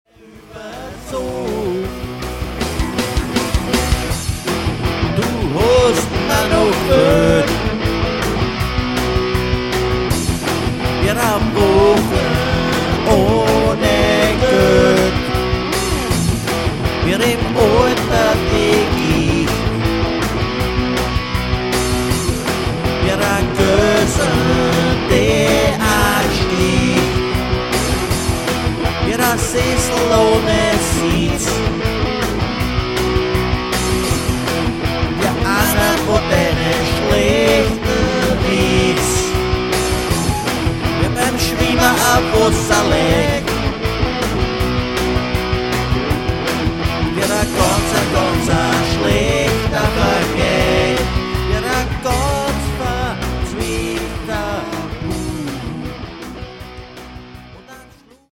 schöne rocknummer, thema: in flagranti